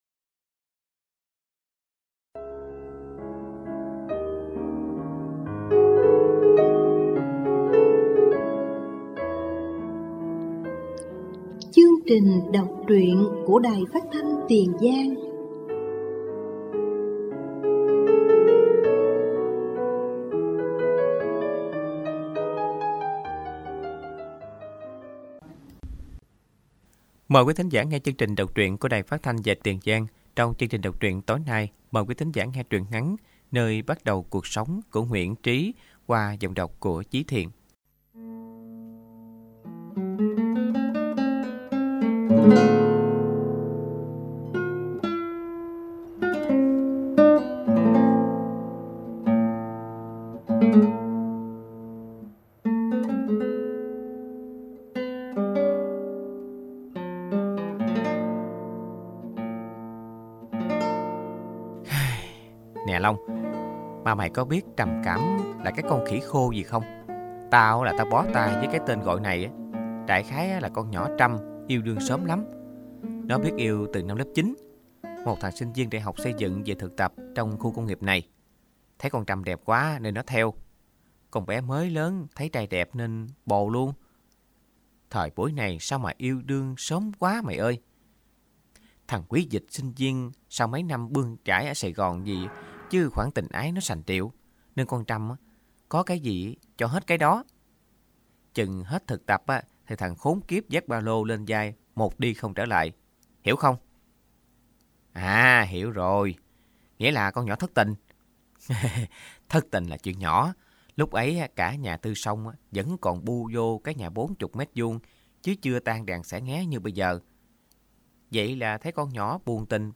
Đọc truyện “Nơi bắt đầu cuộc sống”